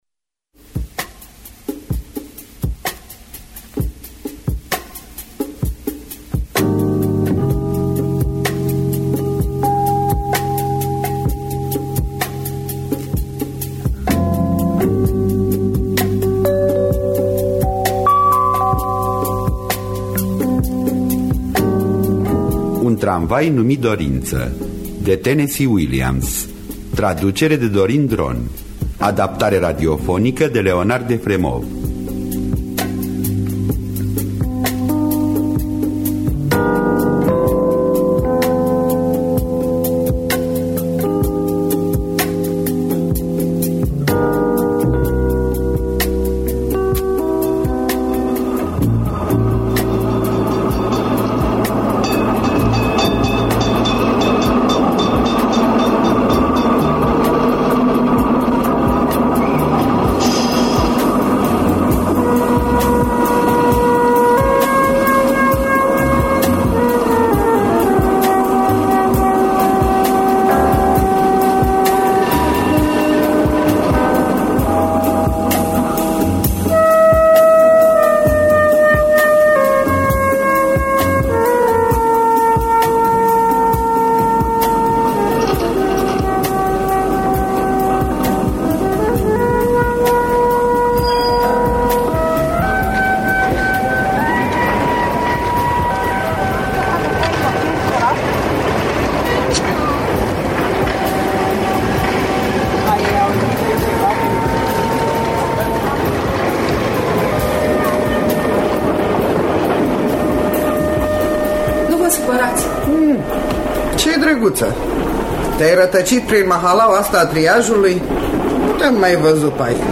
Adaptarea radiofonică
Înregistrare din anul 1983